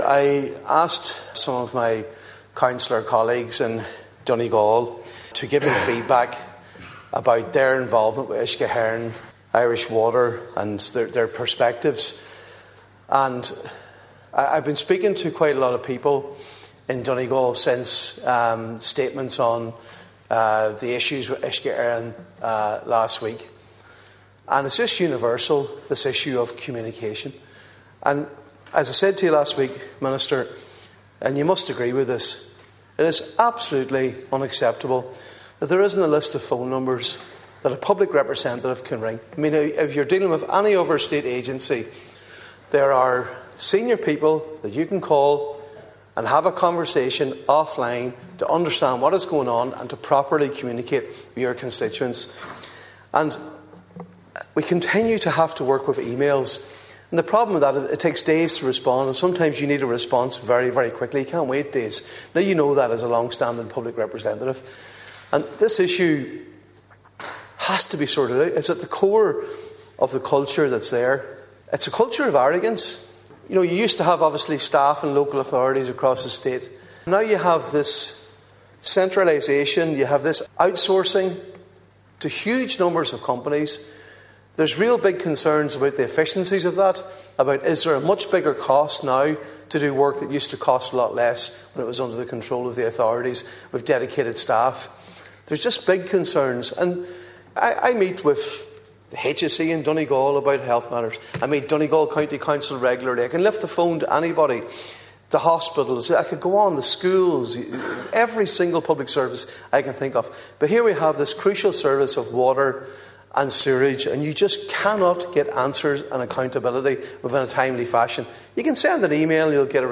The Dail has been told that a lack of communication between Uisce Eireann and local politicians in Donegal is a major issue, with the water utility being accused of displaying a culture of arrogance.